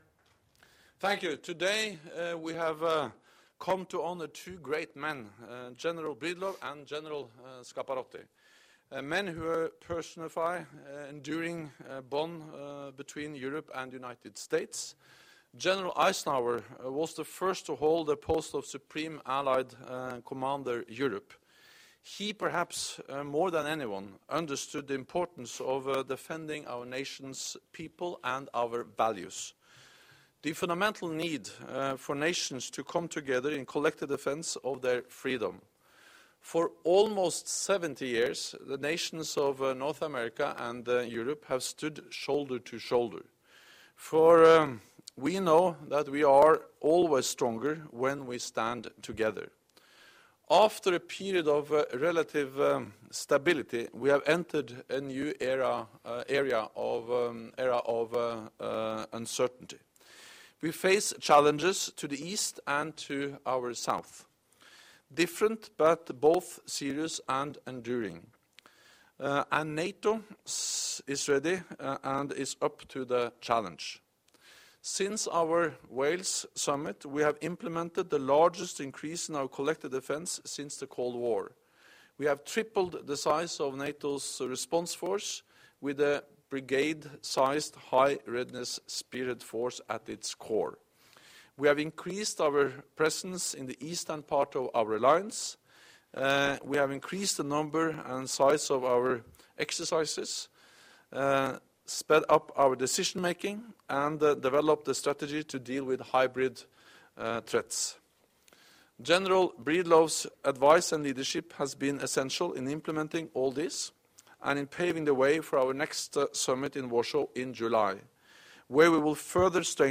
Remarks by NATO Secretary General Jens Stoltenberg at joint press point with incoming SACEUR General Curtis Scaparrotti
Audio Joint press point by NATO Secretary General Jens Stoltenberg and General Curtis M. Scaparrotti 04 May. 2016 | download mp3 News NATO Secretary General welcomes new Supreme Allied Commander Europe 04 May. 2016